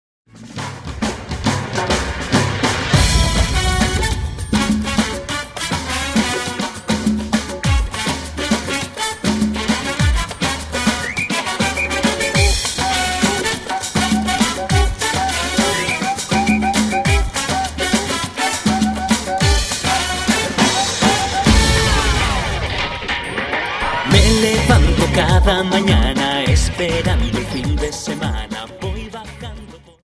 Dance: Samba 51